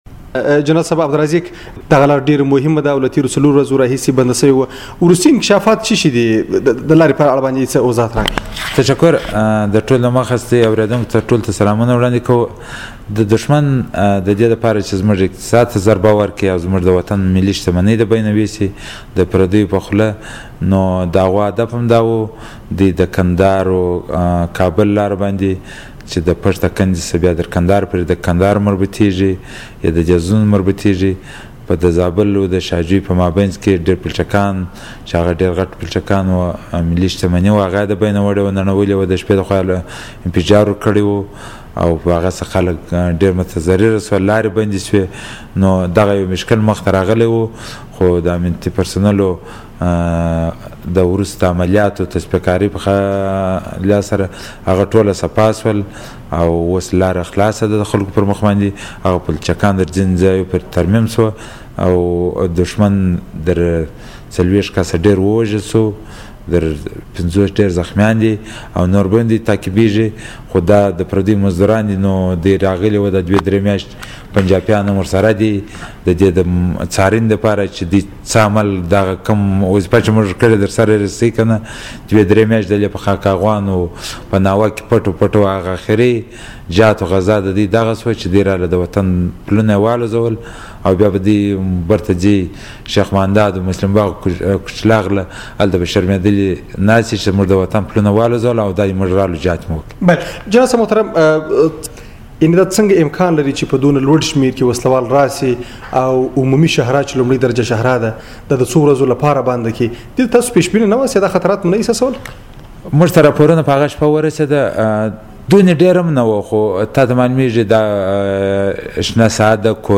جنرال رازق سره مرکه